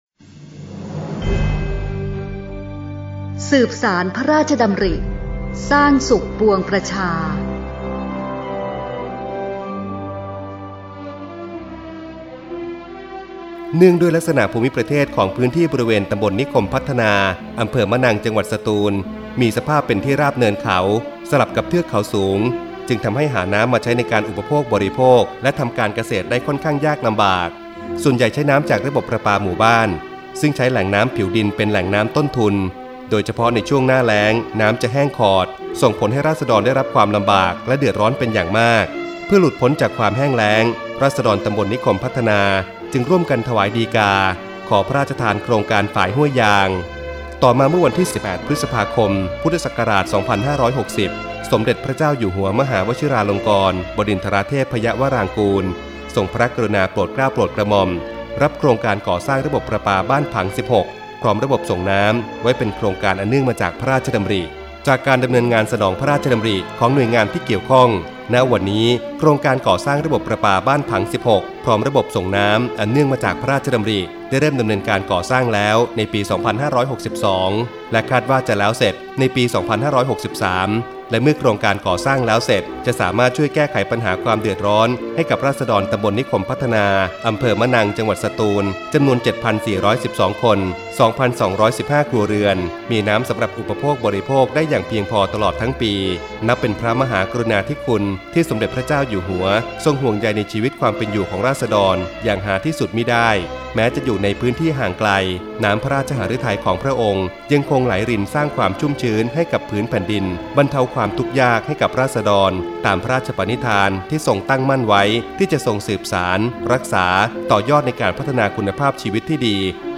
สารคดี